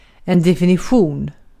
Ääntäminen
IPA : /ˌdɛfɪˈnɪʃ(ə)n/